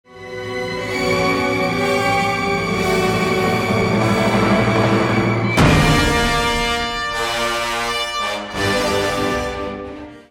ハンマーといっても、金属的な音を出すのでなくて、
鈍い重い音が要求されており、杭打ちに使う木槌が一番ちかいイメージだ。このハンマーでステージ床の上に数枚重ねて置かれた分厚い木の板を叩くような感じだ。
mara6hammer.mp3